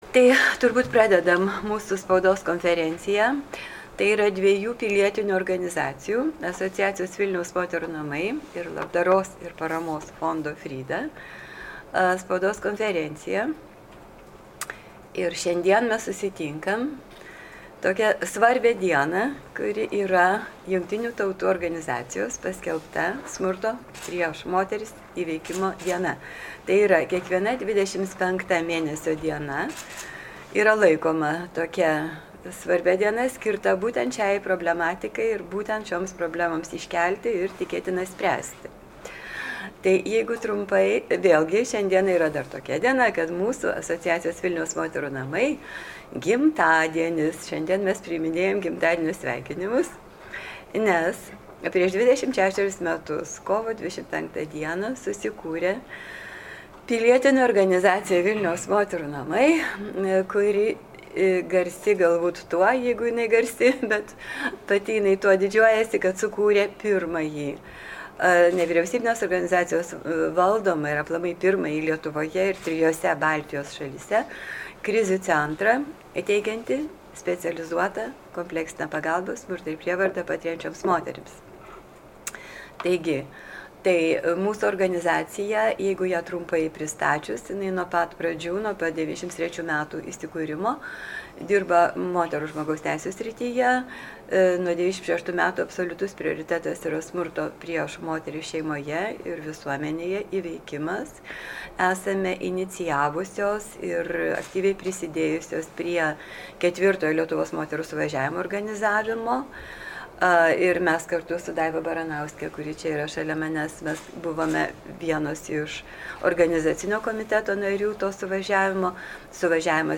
Spaudos konferencijos įrašo galite pasiklausyti čia: